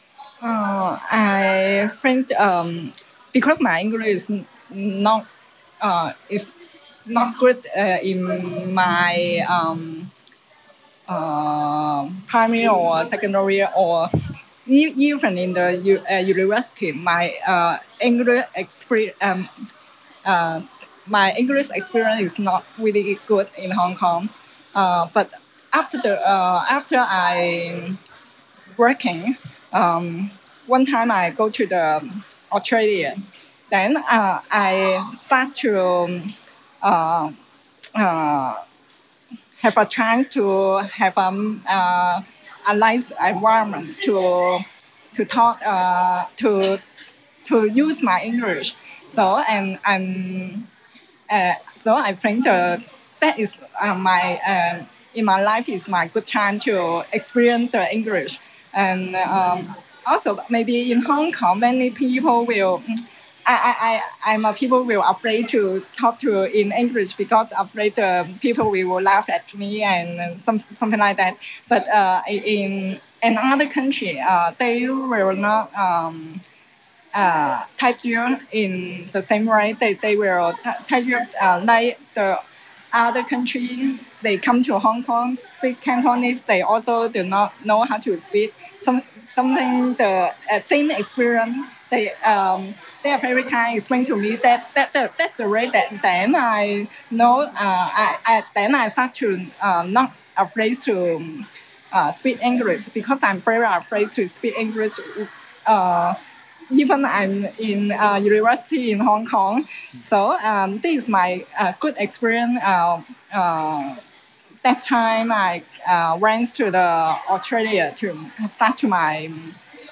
A graduate explains how she overcame her fear of speaking in English during a trip to Australia. She says students should practise and not be too self-conscious.